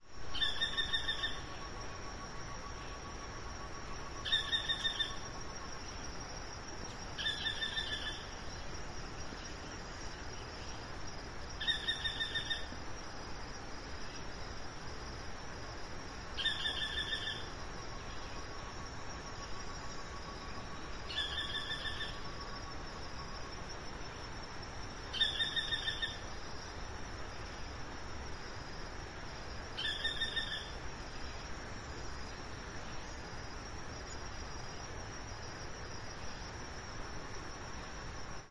White-eyed Foliage-gleaner (Automolus leucophthalmus)
Location or protected area: Parque Nacional Ybycui
Condition: Wild
Certainty: Observed, Recorded vocal
Automolus-leucophthalmus--1-.mp3